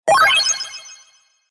ピコピコとした音が小気味良く鳴り、急に増幅されたような爆風音。
8bit時代のゲームを彷彿とさせる、懐かしくも臨場感あふれる爆風効果音です。